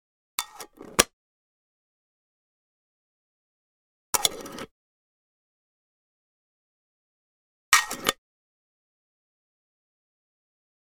Metal Film Can Replace Lid Sound
household